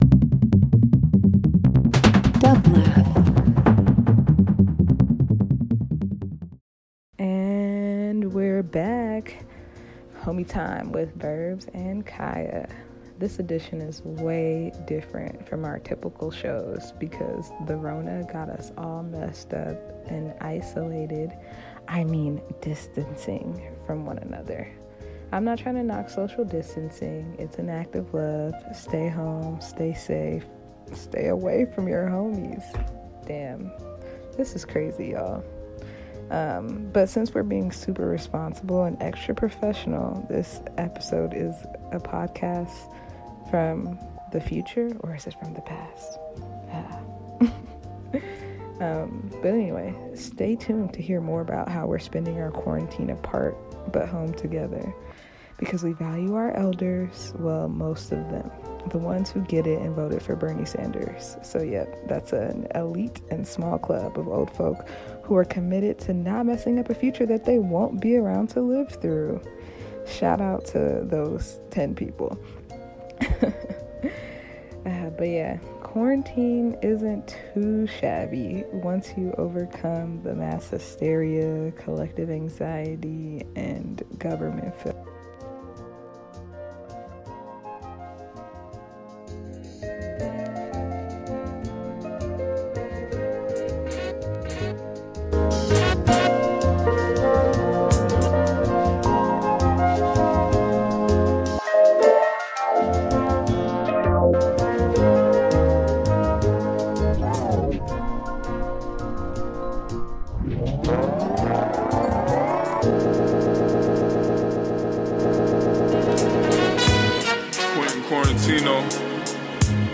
Hip Hop Indie Talk Show